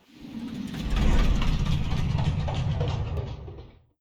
Slow Down Time Sound Effect.wav